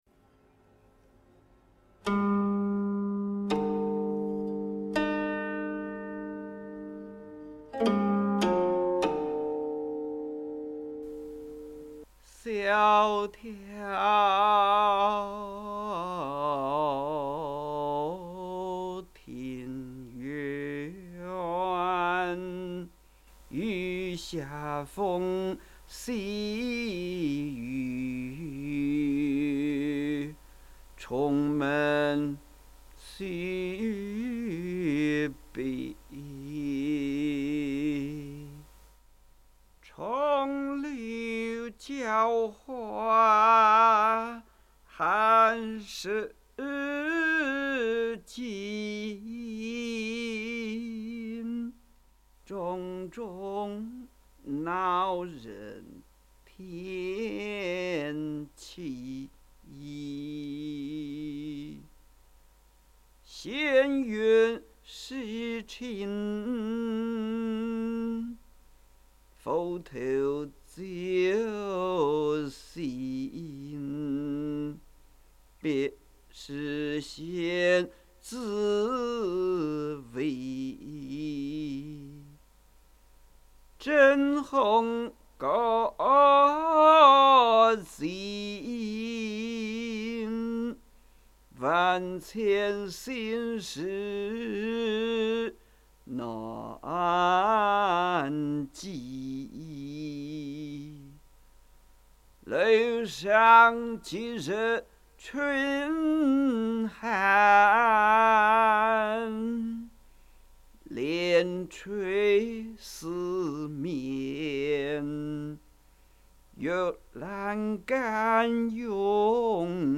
吟誦